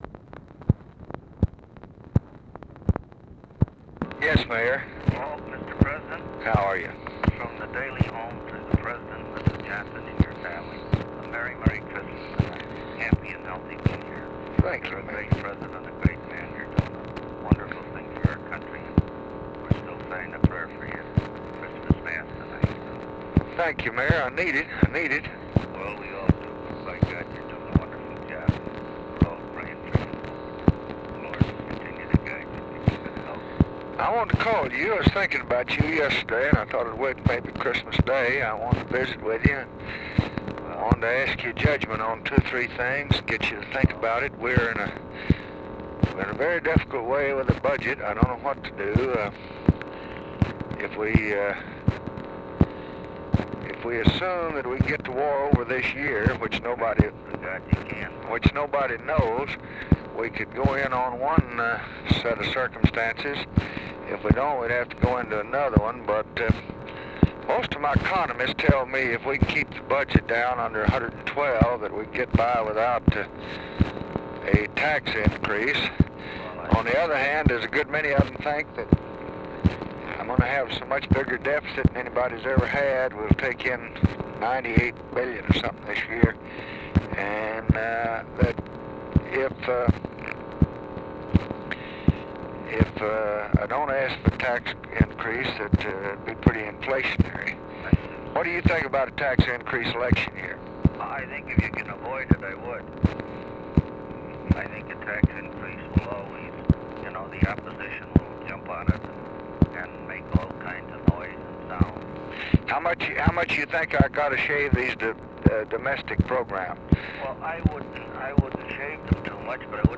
Conversation with RICHARD DALEY, December 24, 1965
Secret White House Tapes